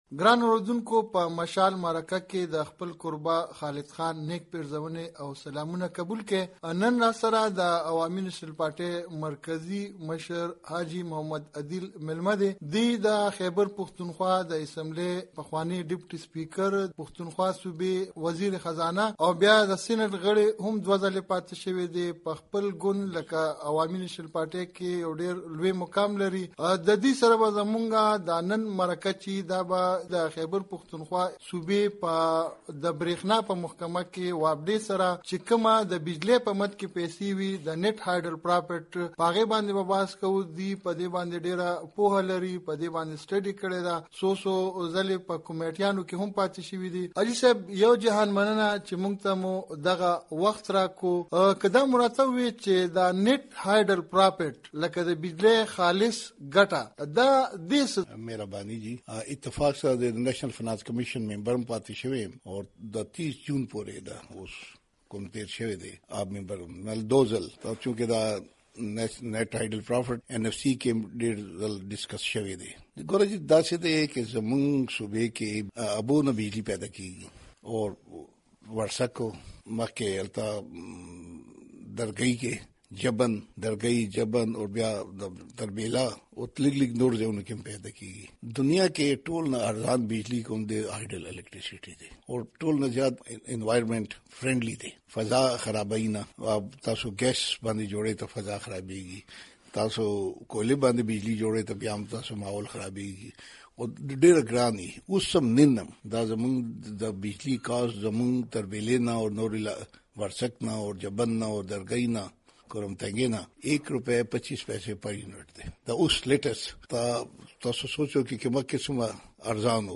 د مشال مرکه / حاجي عدیل